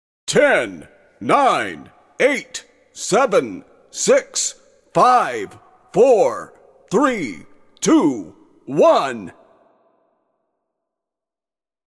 countdown10sec.ogg